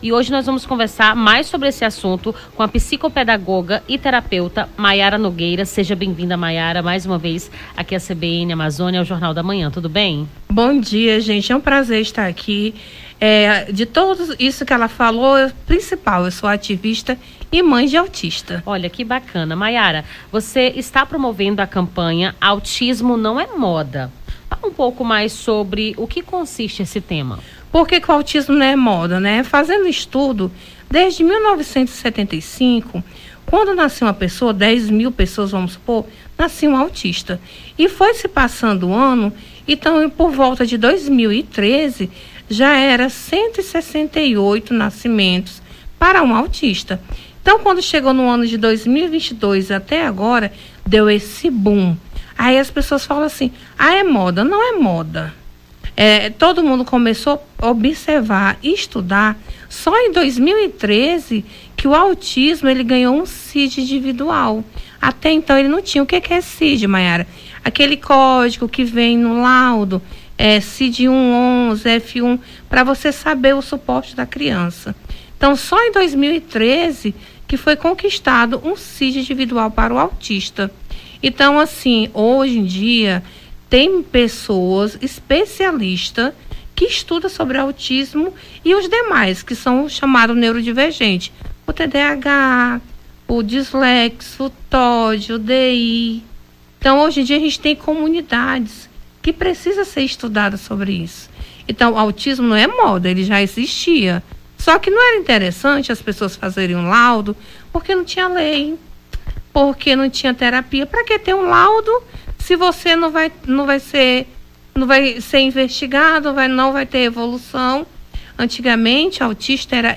as apresentadoras
conversaram com a psicopedagoga e terapeuta
Nome do Artista - CENSURA - ENTREVISTA (AUTISMO NAO E MODA) 18-07-24.mp3